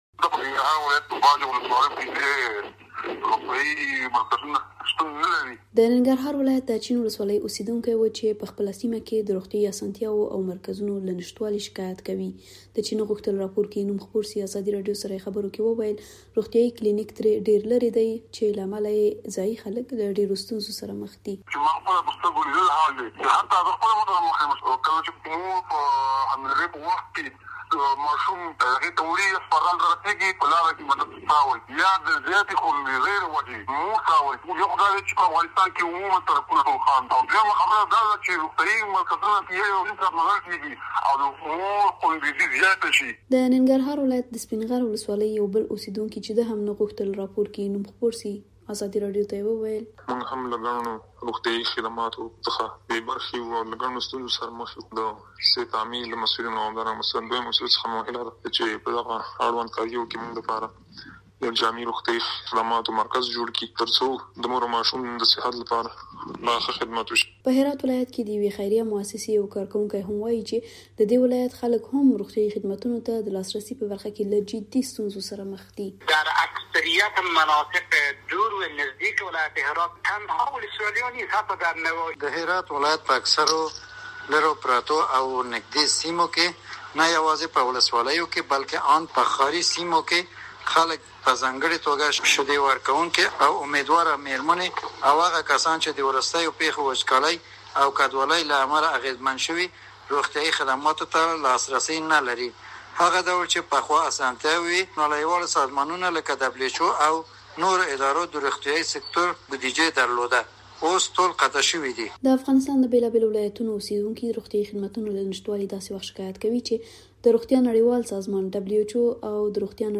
مرکه - صدا